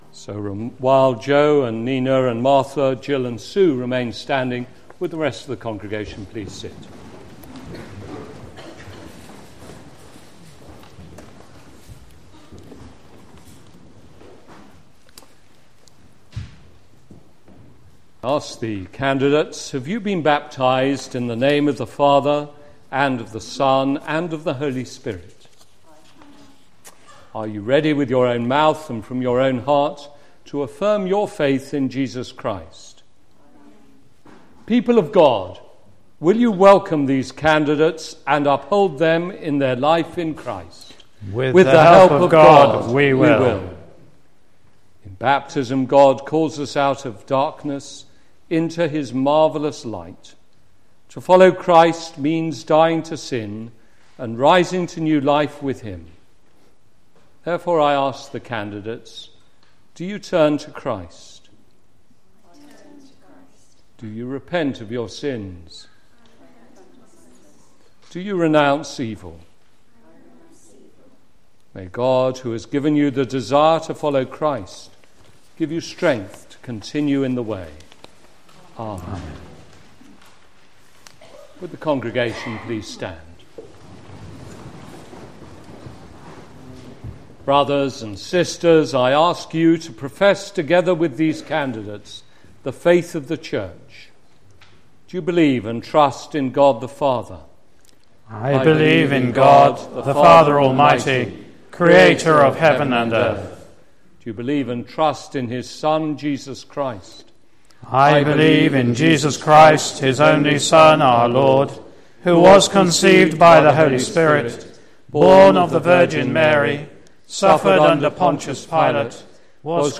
Confirmation Service led by Bishop Colin Fletcher